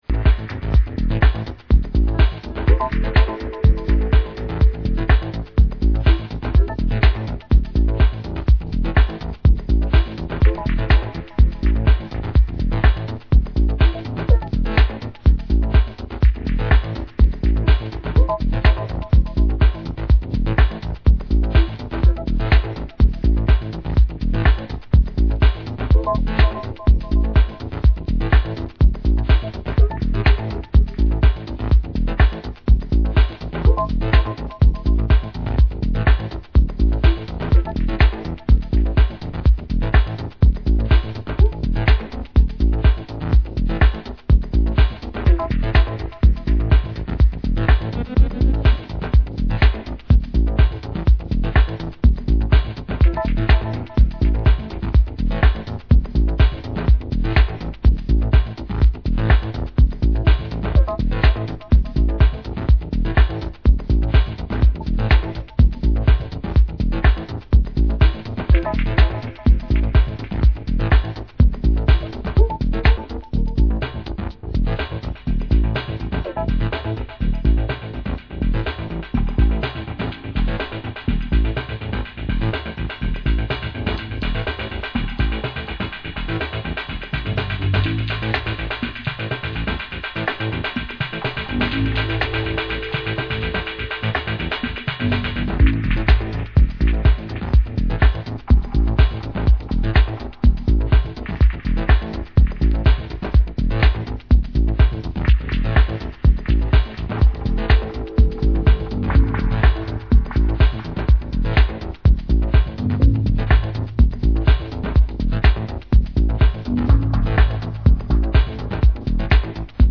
warm atmospheres and spaces